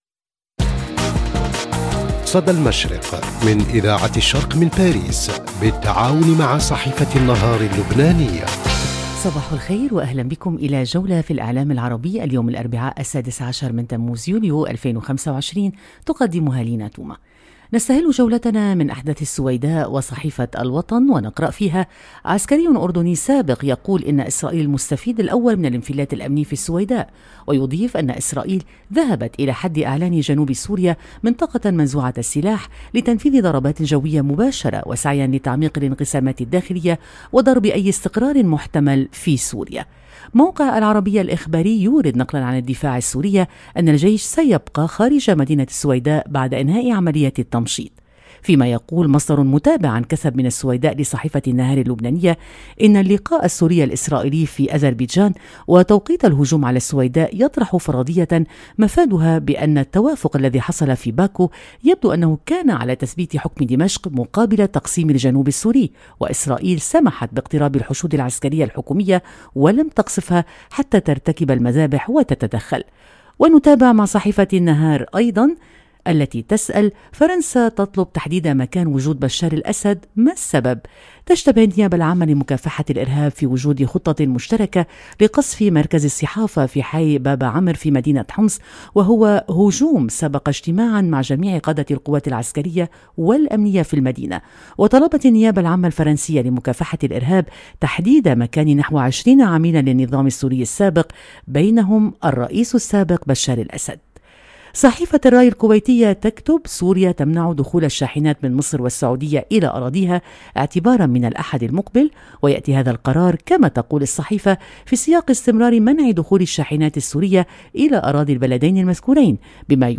صدى المشرق – نافذتك اليومية على إعلام الشرق، كل صباح على إذاعة الشرق بالتعاون مع جريدة النهار اللبنانية، نستعرض أبرز ما جاء في صحف ومواقع الشرق الأوسط والخليج من تحليلات ومواقف ترصد نبض المنطقة وتفكك المشهد الإعلامي اليومي.